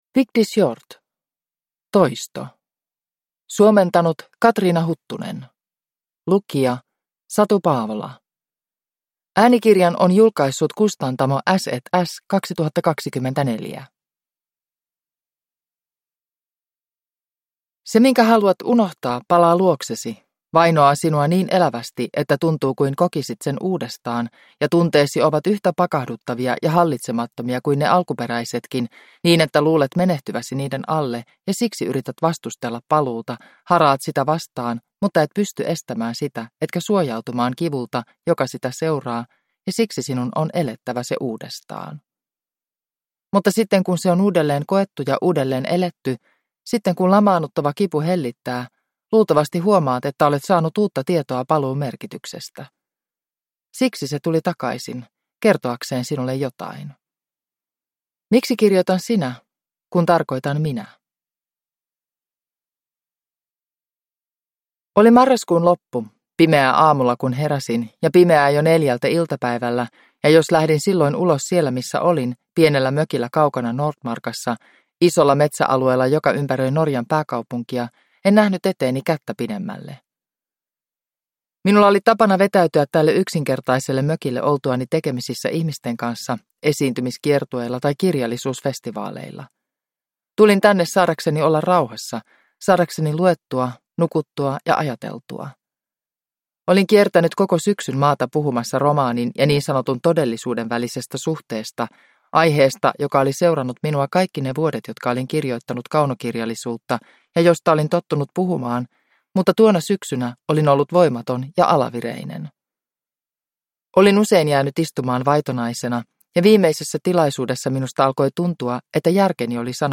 Toisto (ljudbok) av Vigdis Hjorth